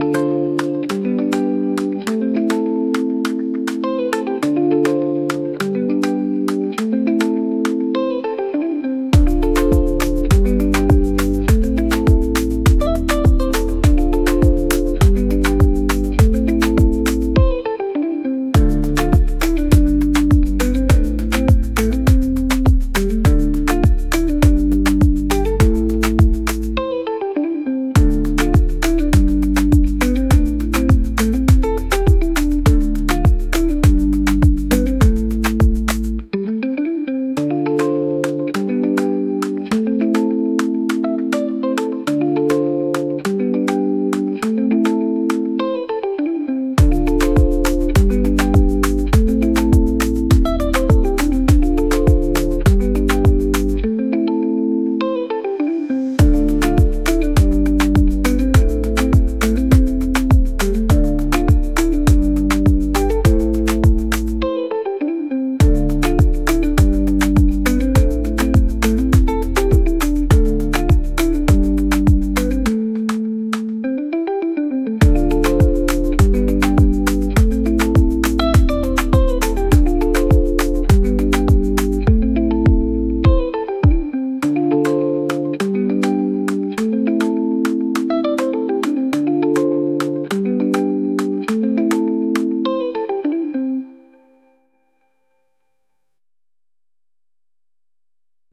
Afrobeat
Hopeful
136 BPM